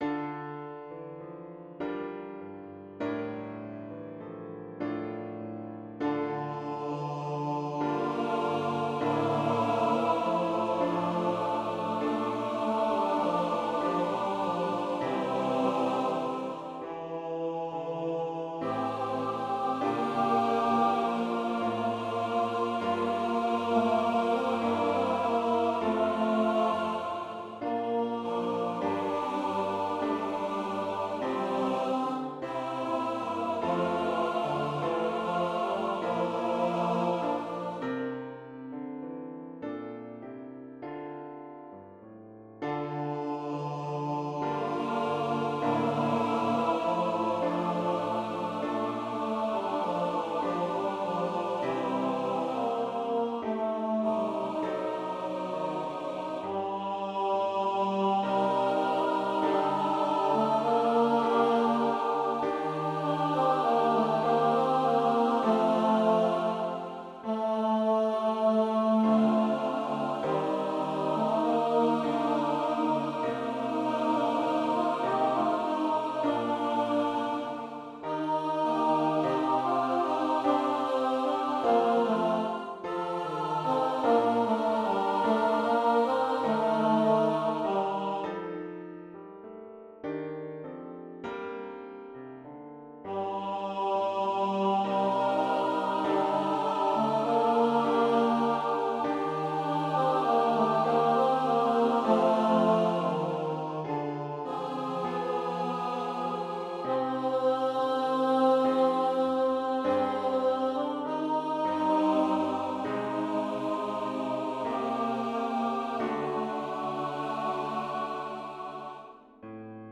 1 solo tenor (Jesus) 1 solo baritone (Judas)
1 countertenor, 4 tenors, and 6 basses (Disciples)
Voicing/Instrumentation: TB , TTB See more from Joseph Knapicius .